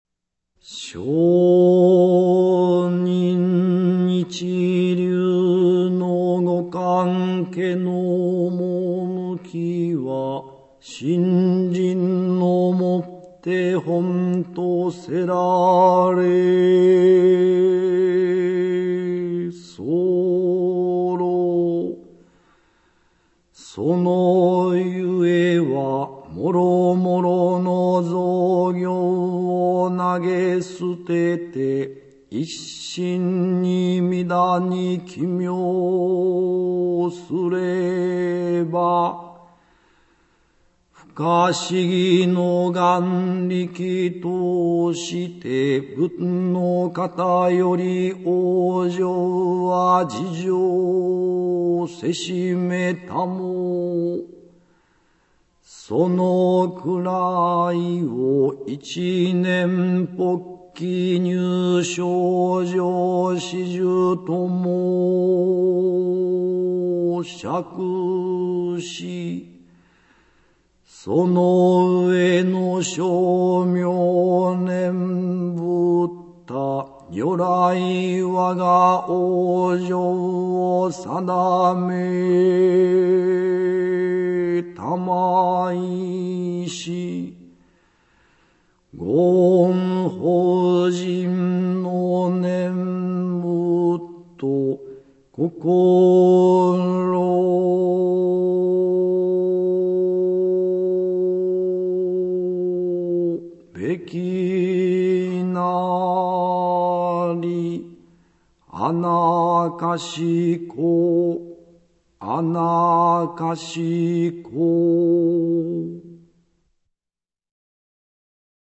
Leitura cantada da Primeira Carta de Rennyo Shonin, (1415-1495), o Oitavo Patriarca da Terra Pura.